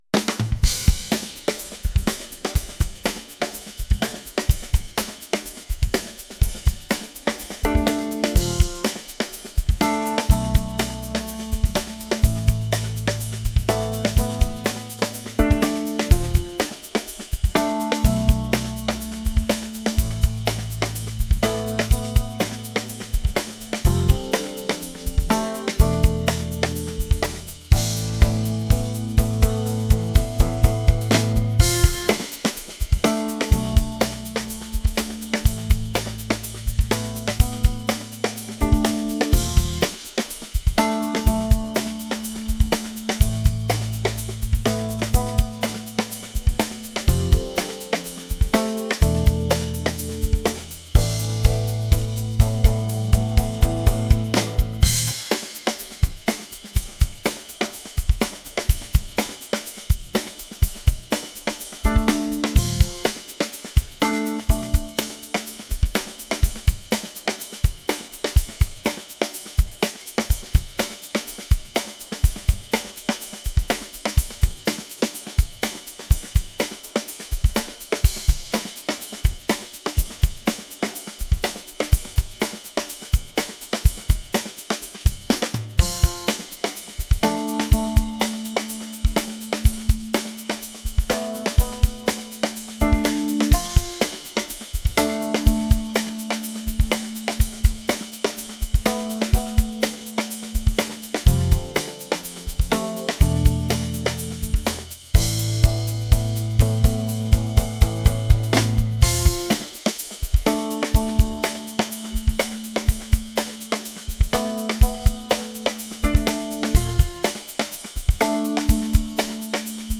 funky | fusion | jazz